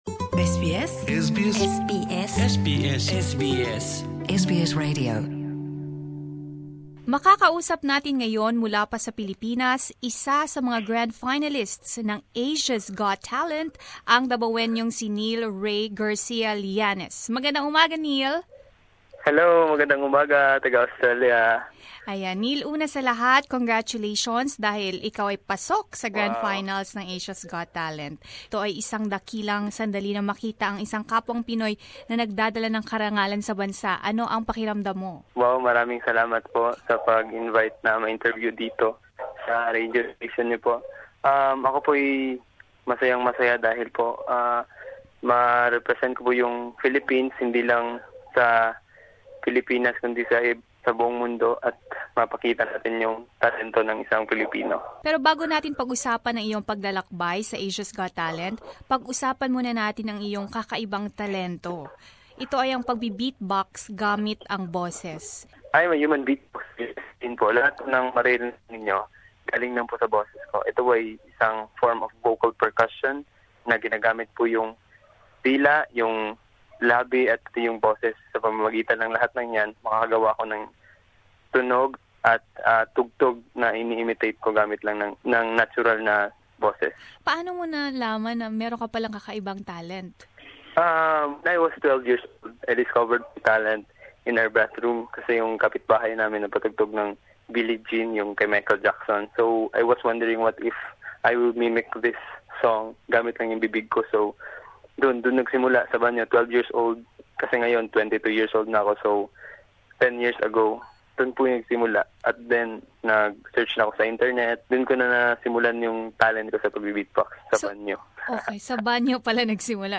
Narito ang ating panayam sa kilalang Pinoy human beatboxer tungkol sa kanyang mga paghahanda para sa nalalapit na pagtatapos ng kompetisyon.